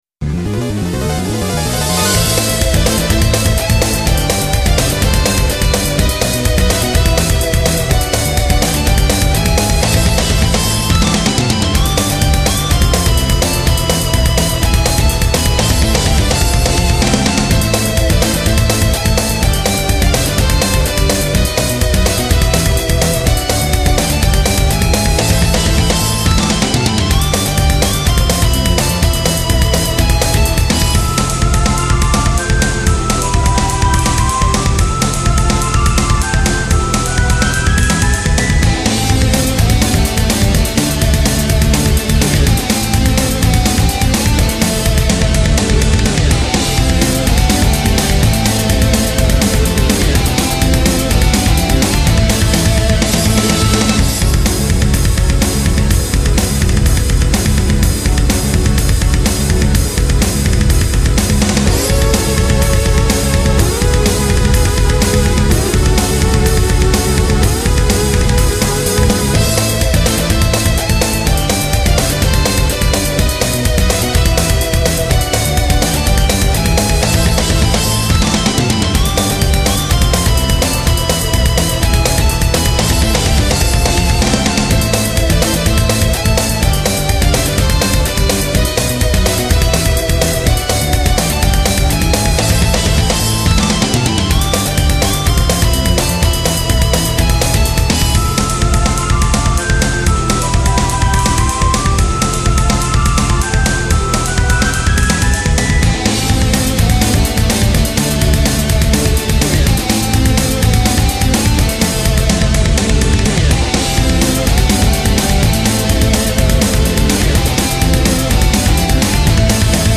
YAMAHA MU2000を使ってゲームBGMのメタルアレンジをしています